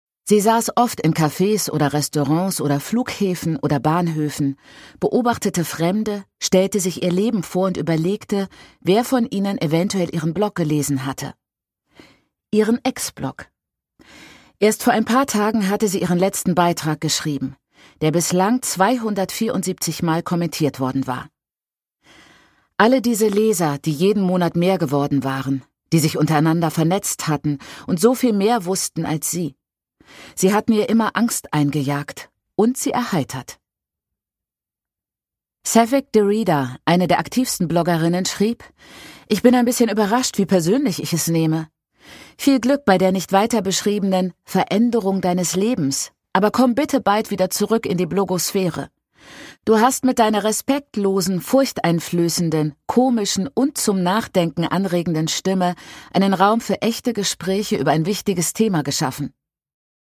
Chimamanda Ngozi Adichie: Americanah (Ungekürzte Lesung)
Produkttyp: Hörbuch-Download